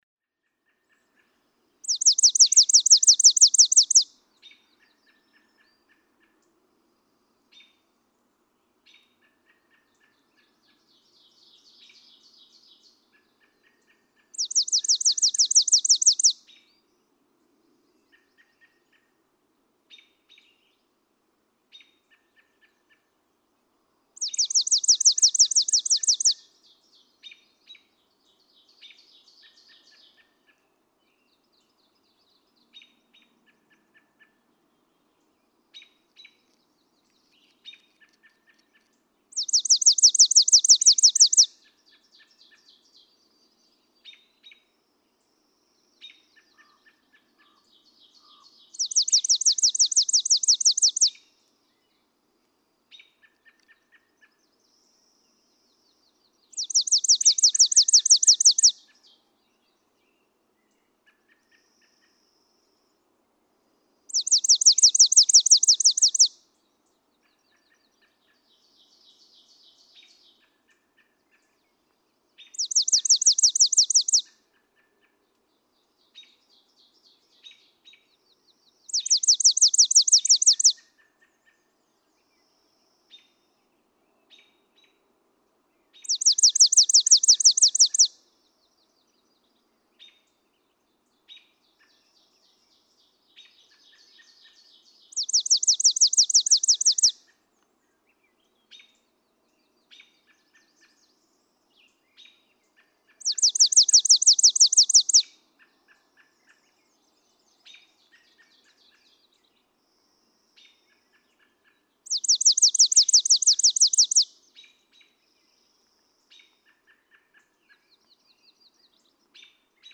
Chipping sparrow
An example of longish daytime songs, typically given while a male is perched high in a tree.
Mt. Greylock State Park, North Adams, Massachusetts.
497_Chipping_Sparrow.mp3